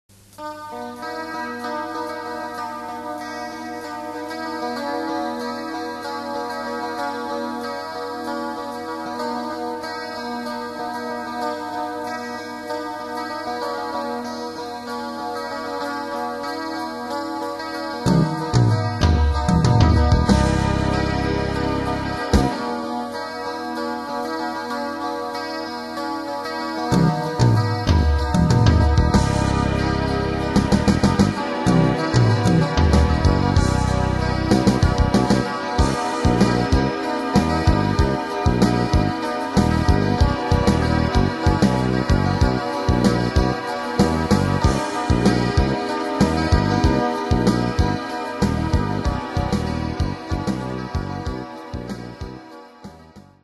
後に「イマジナリータイム」という曲名で完成した曲のイントロ部分ですね。プログレ色が濃く展開も多い曲で、バンド練習が大変でした。